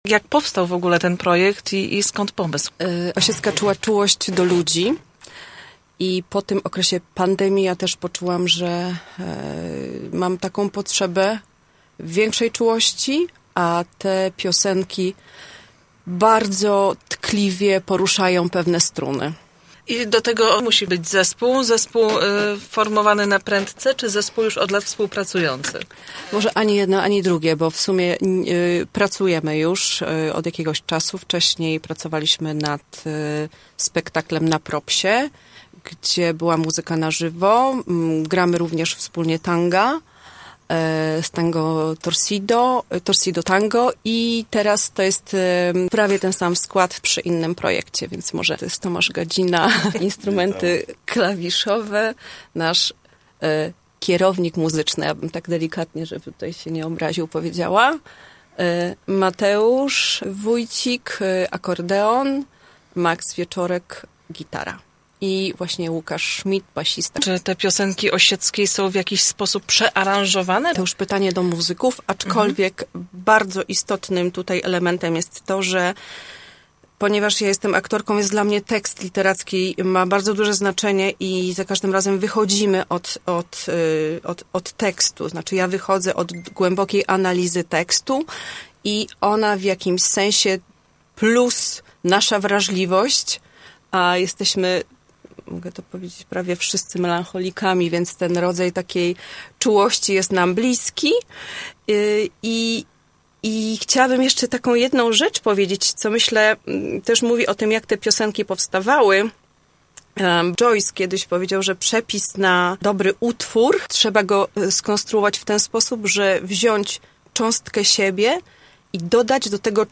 wywiad [5.26 MB]